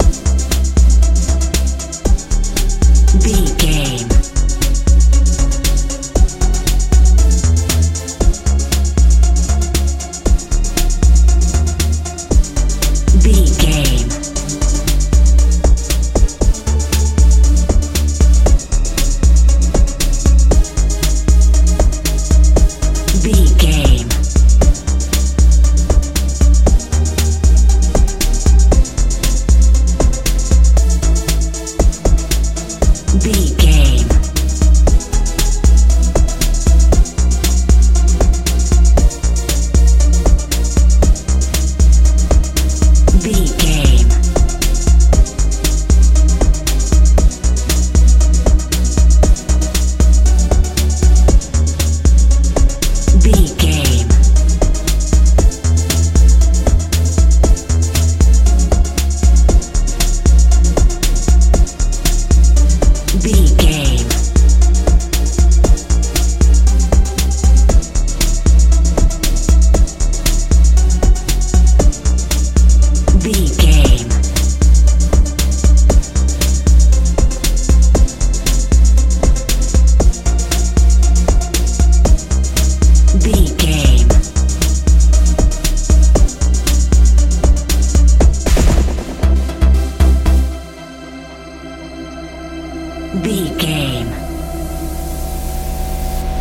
dance feel
Ionian/Major
C♯
energetic
hypnotic
synthesiser
bass guitar
drum machine
drums
80s
90s
strange
suspense